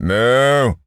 Animal_Impersonations
cow_moo_09.wav